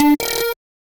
Index of /phonetones/unzipped/Google/Android-Open-Source-Project/notifications/ogg